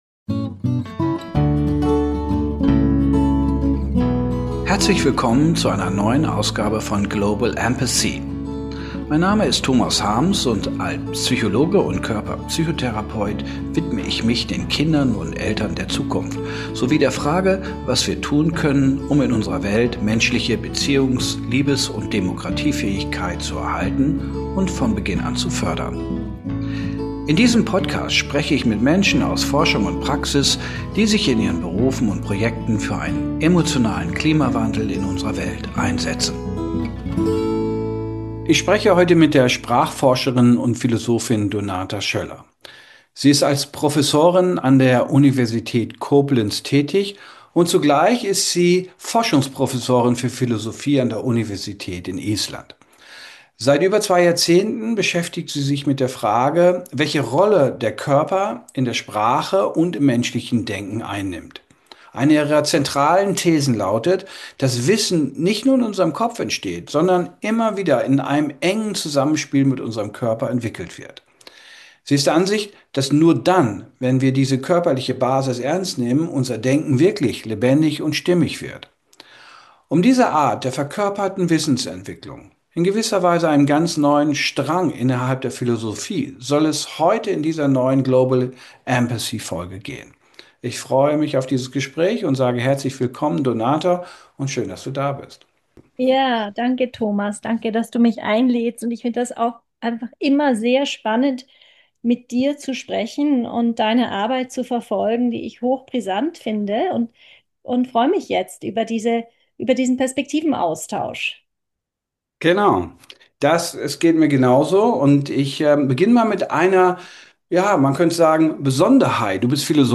Ein Gespräch über Sprache, Resonanz und die Kraft einer lebendigen Selbstwahrnehmung.